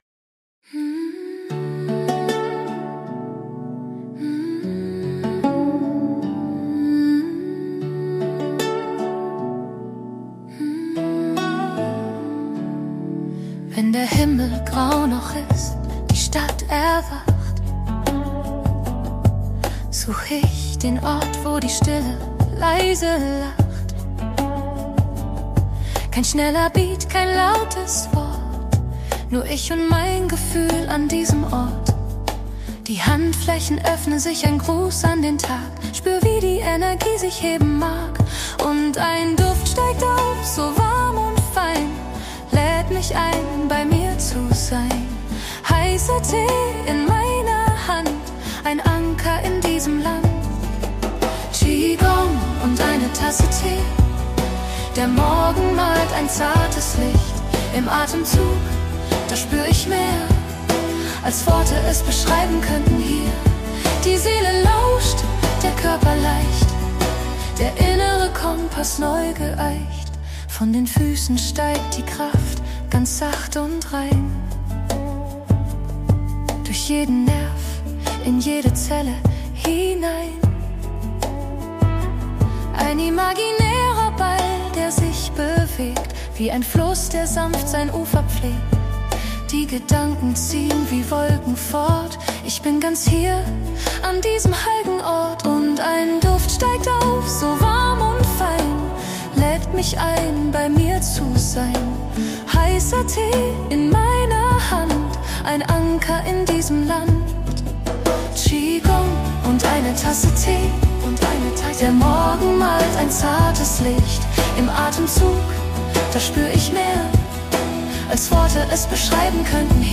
Soundtrack zum Podcast